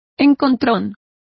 Complete with pronunciation of the translation of collisions.